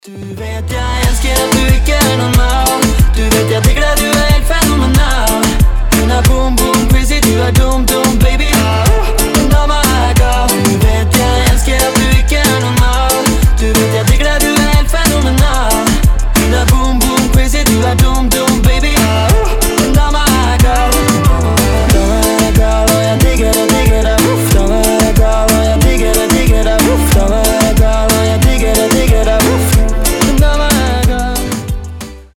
• Качество: 320, Stereo
поп
мужской вокал
Moombahton
Reggaeton
Заводной норвежский поп